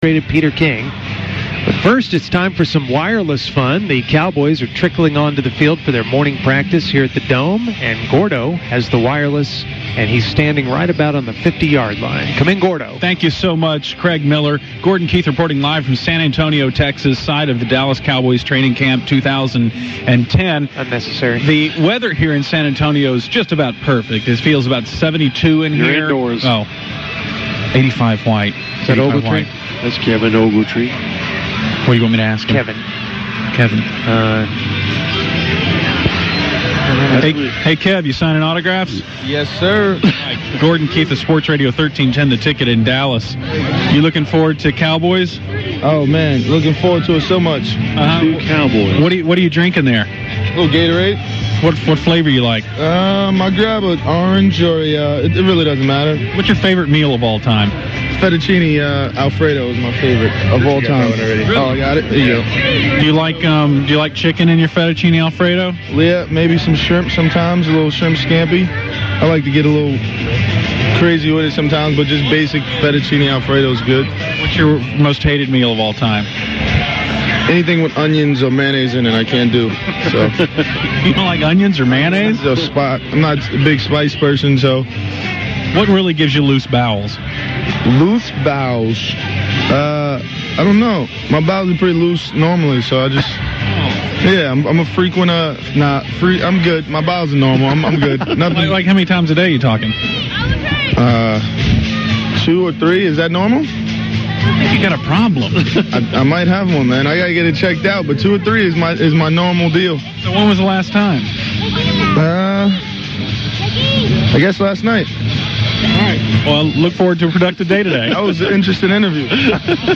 Then another surprise chat with a familiar Cowboy’s coach, Dave Campo. During their conversation a very high pitched woman stops the show down.